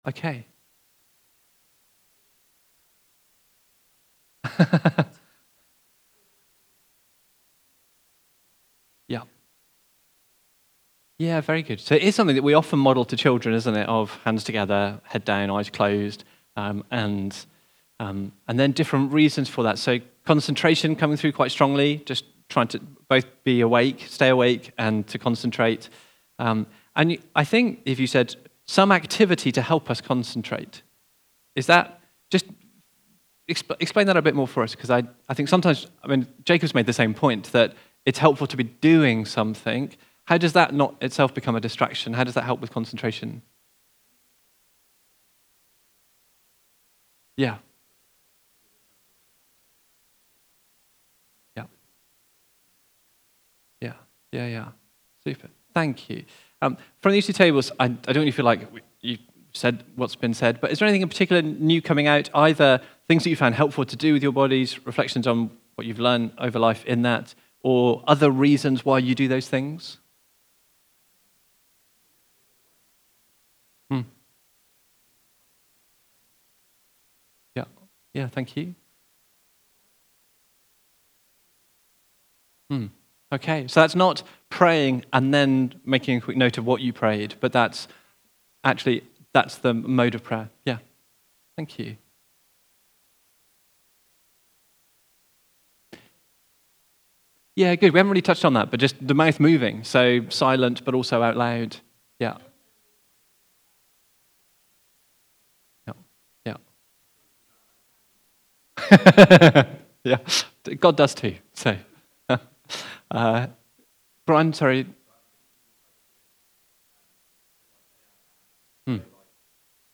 Recorded at Woodstock Road Baptist Church on 25 May 2025.